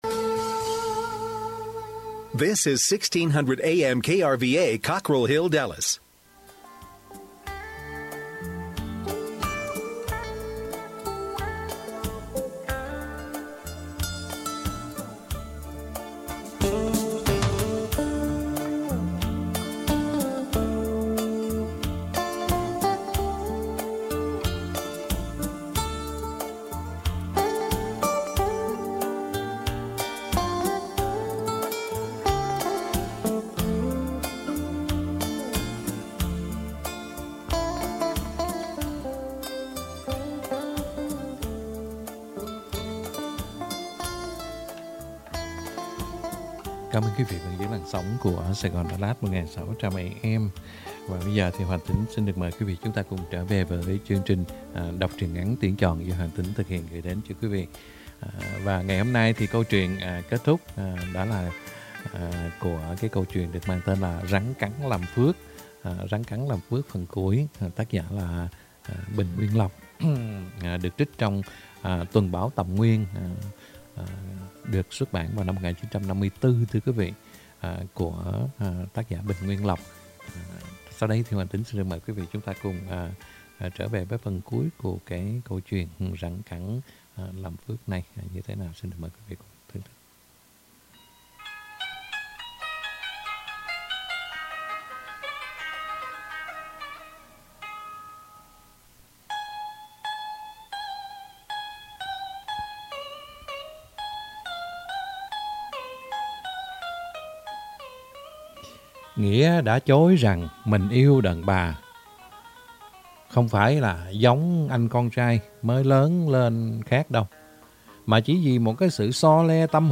Đọc Truyện Ngắn = Rắn cắn làm phước (2 end) - 11/09/2021 . | Radio Saigon Dallas - KBDT 1160 AM